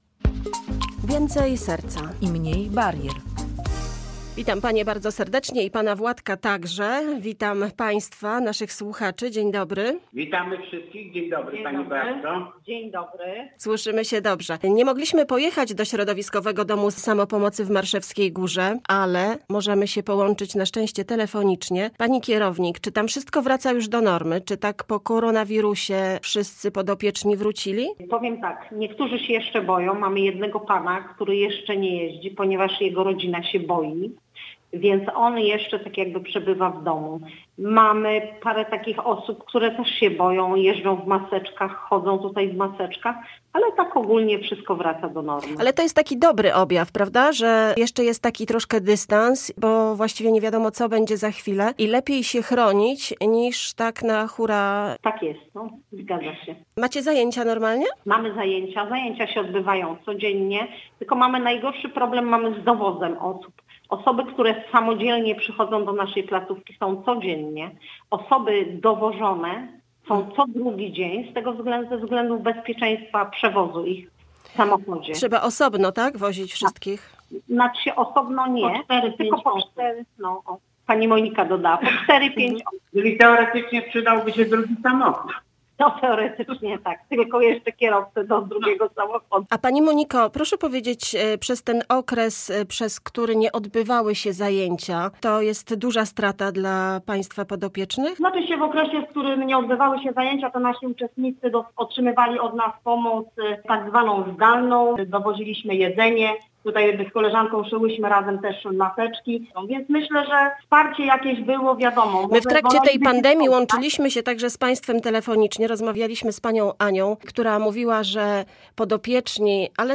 O sytuacji w ośrodku rozmawialiśmy w programie Więcej serca i mniej barier.